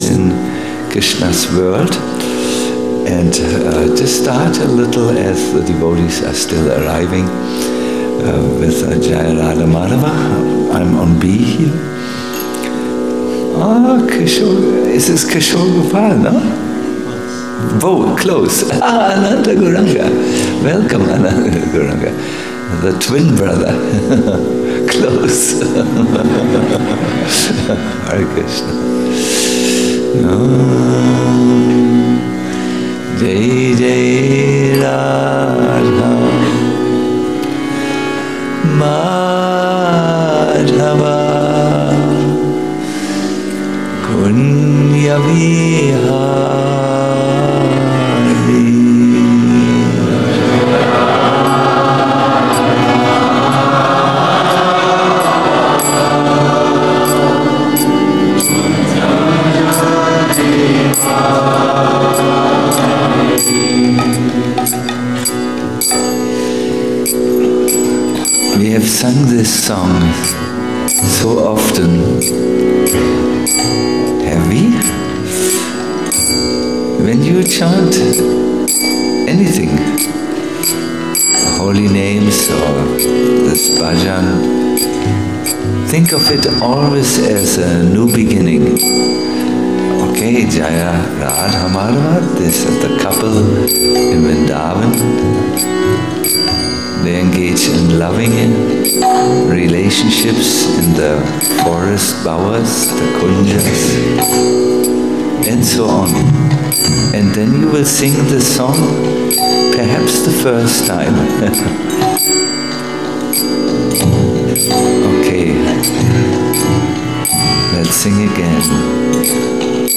A lecture
Govardhana Retreat Center